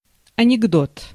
Ääntäminen
IPA: /mɔp/